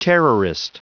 Prononciation du mot terrorist en anglais (fichier audio)
Prononciation du mot : terrorist